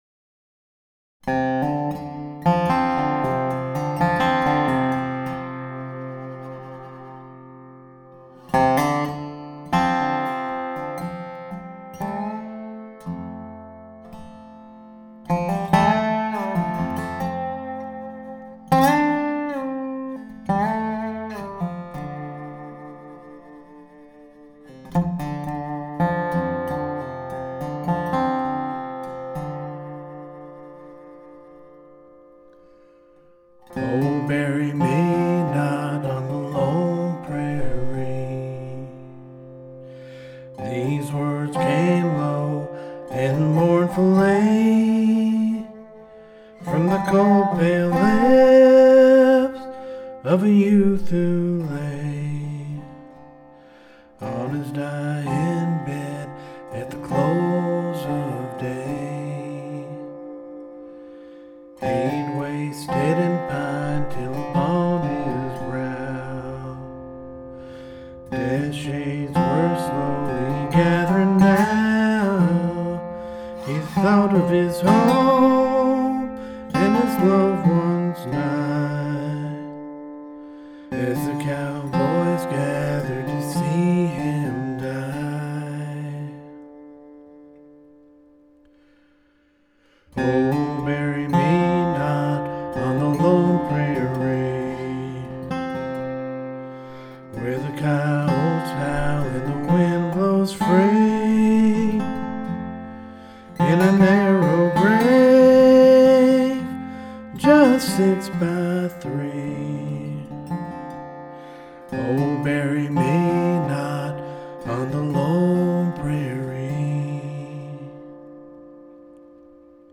Solo Dobro Part 1